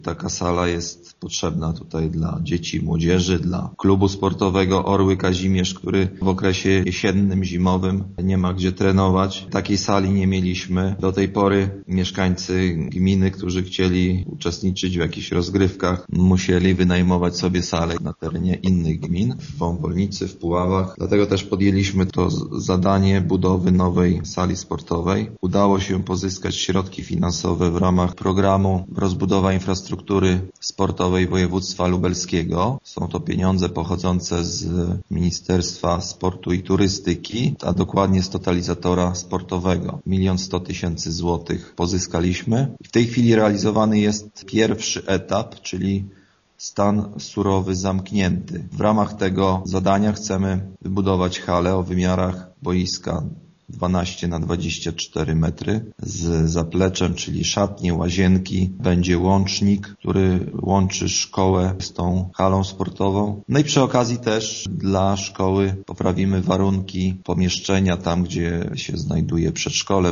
Burmistrz Kazimierza Grzegorz Dunia nie ma wątpliwości, że hala sportowa jest w gminie niezbędna: